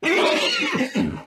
animalia_horse_hurt.ogg